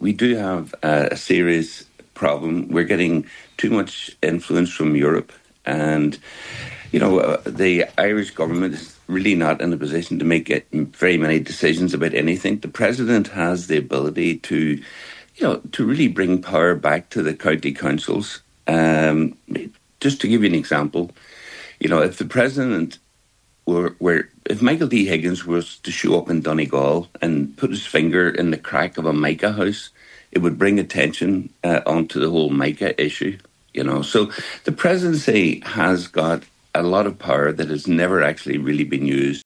on this morning’s Nine ’til Noon show, Mr. Casey says he believes Europe has too much influence on the Irish Government, and this is something he would change: